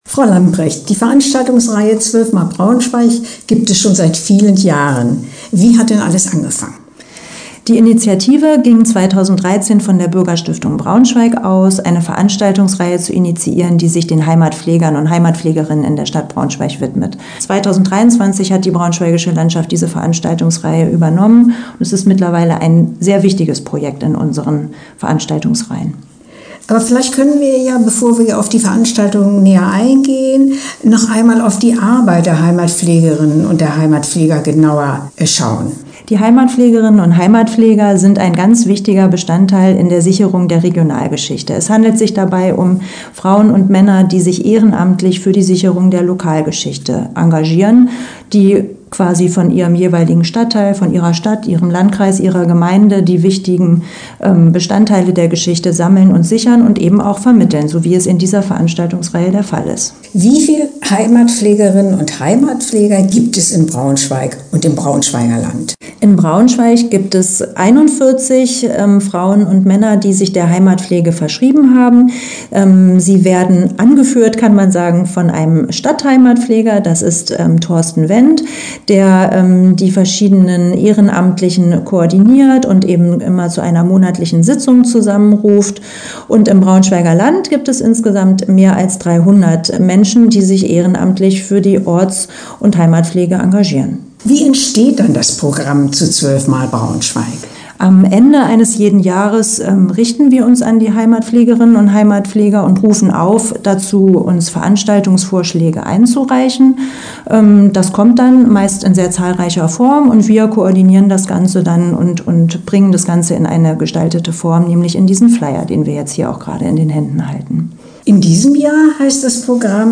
Interview-12-x-Braunschweig.mp3